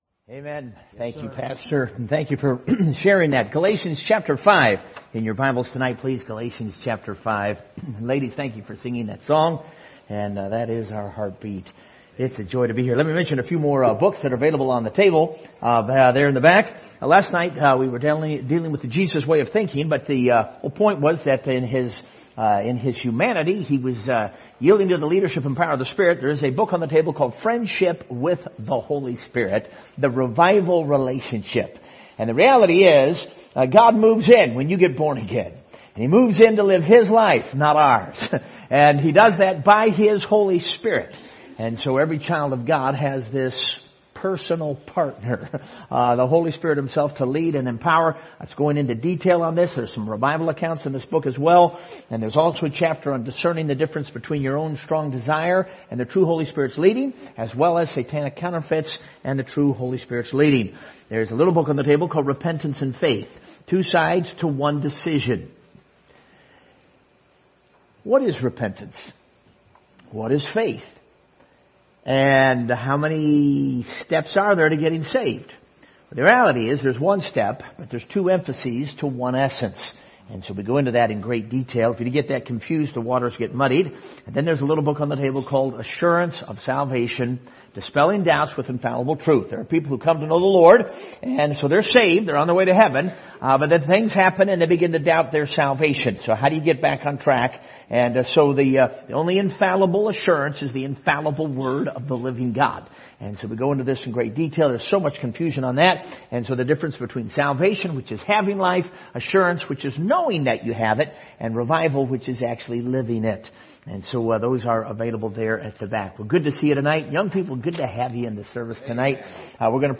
Passage: Galatians 5:16-25 Service Type: Revival Service